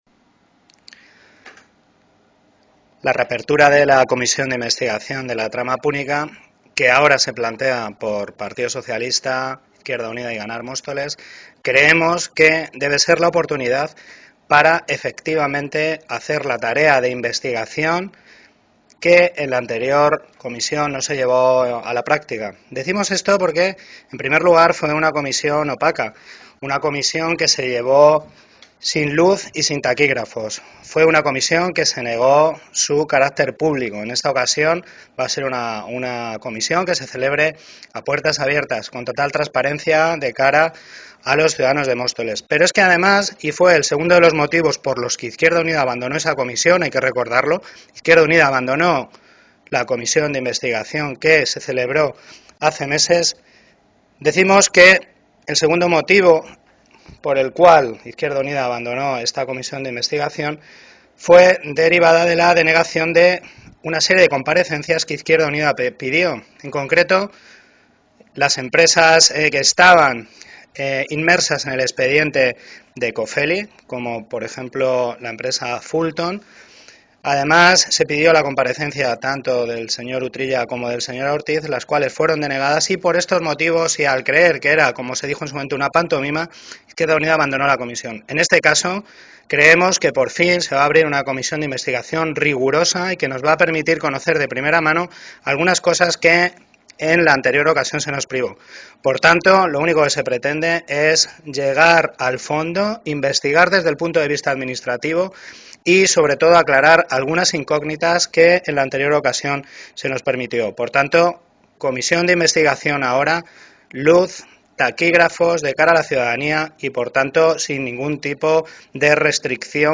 Audio - Eduargo Gutierrez (Concejal de Urbanismo y Vivienda) Sobre Trama Púnica